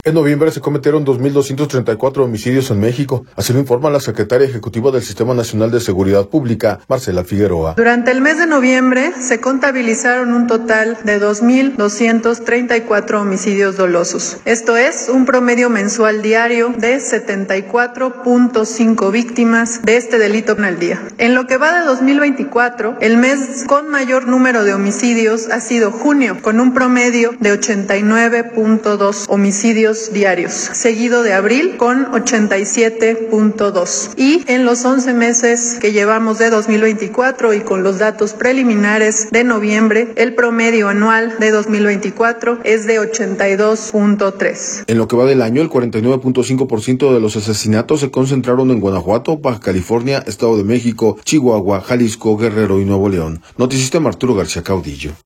En noviembre se cometieron dos mil 234 homicidios en México, así lo informa la secretaria ejecutiva del Sistema Nacional de Seguridad Pública, Marcela Figueroa.